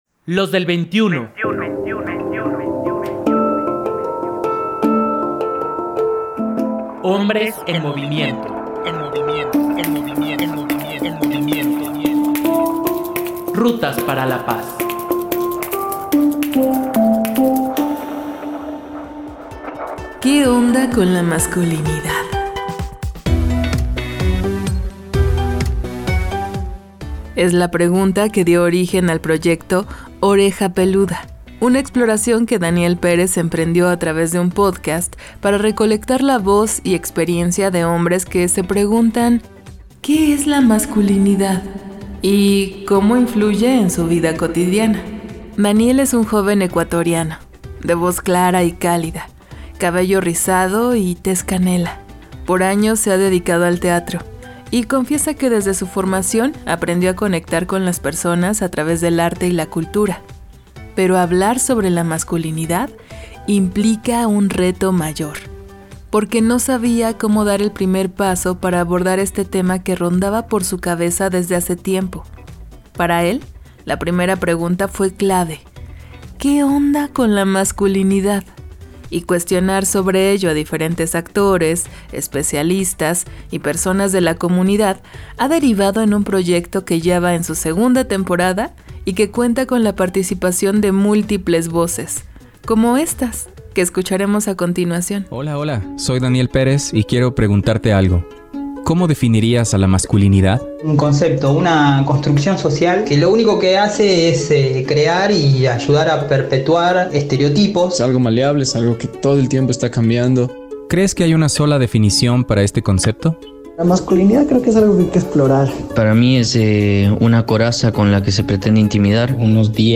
de voz clara y cálida